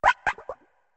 tatsugiri_ambient.ogg